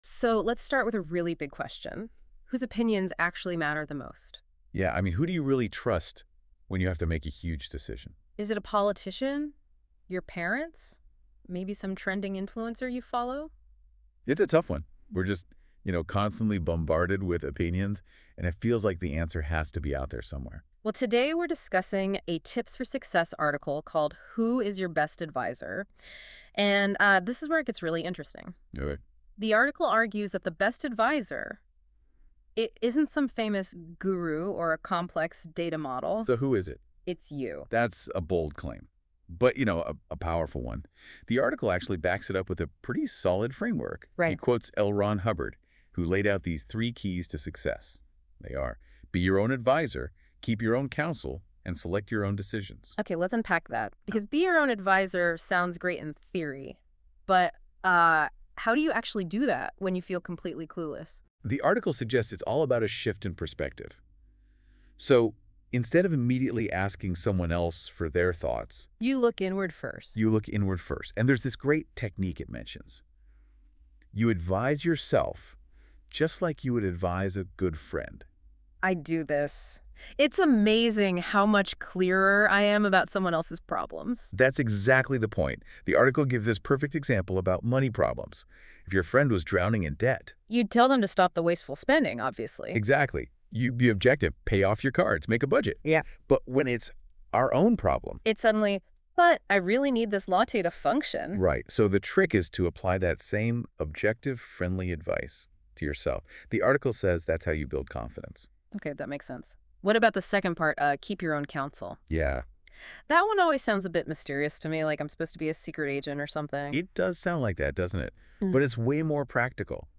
• It can also create images, videos and even audio discussions, like the one at the top of this article 🙂